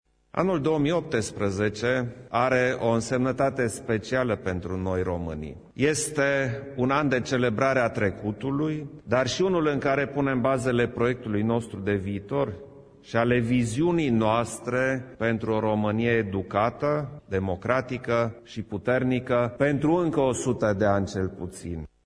Şeful statului a afirmat, în cadrul unei ceremonii care a avut loc la Palatul Cotroceni, că generaţia actuală are o mare responsabilitate în conturarea destinului ţării.